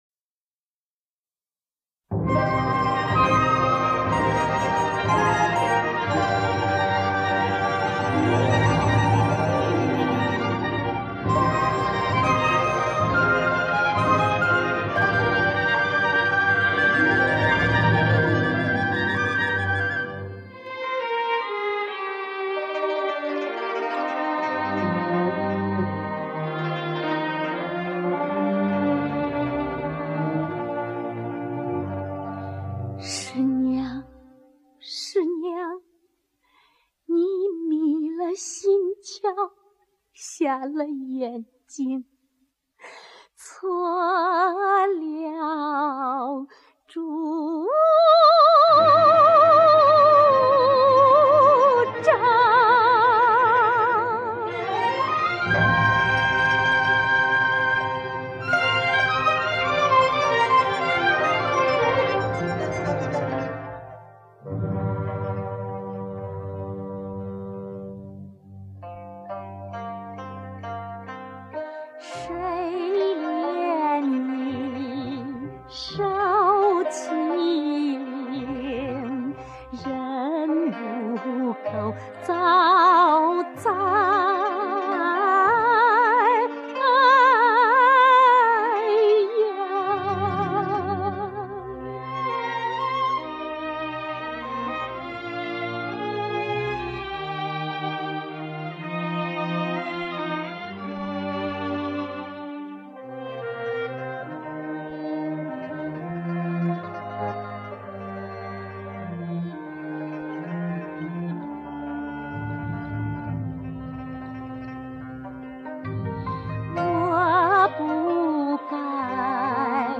被删除的插曲